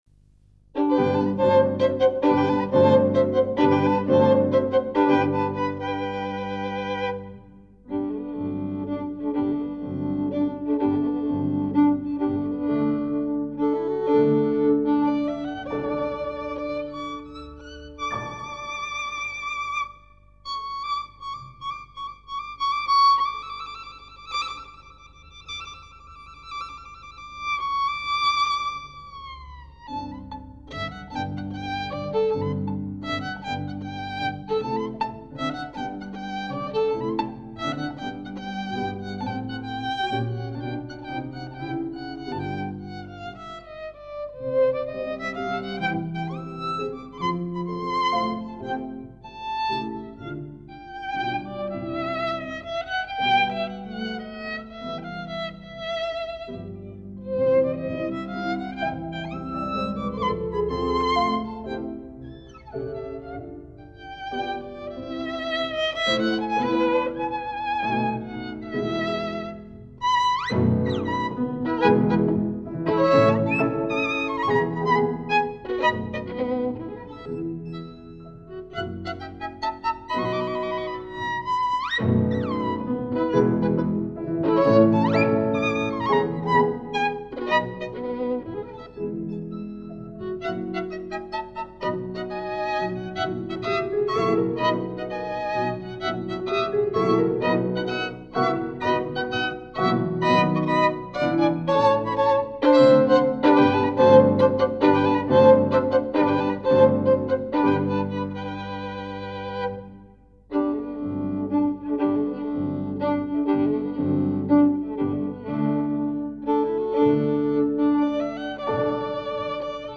Niektóre utwory są koncertowe dlatego taka jakośc nagrań.
skrzypce